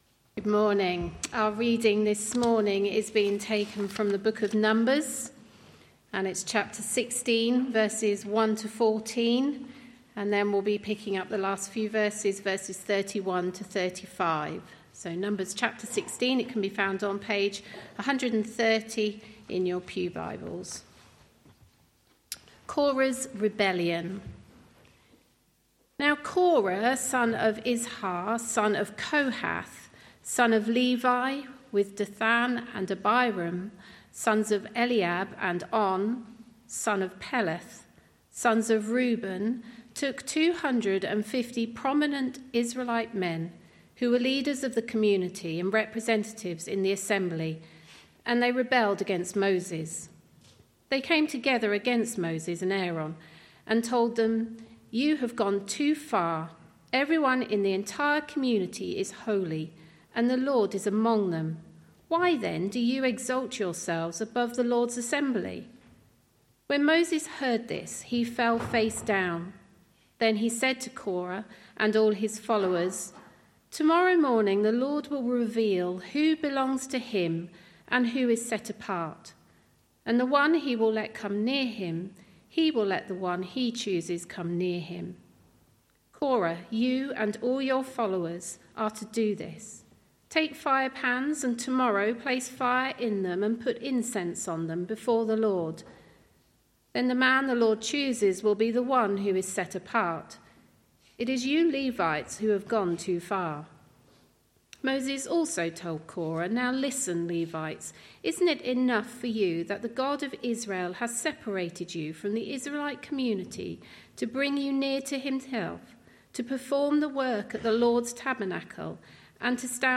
Sermons - TCM Baptist Church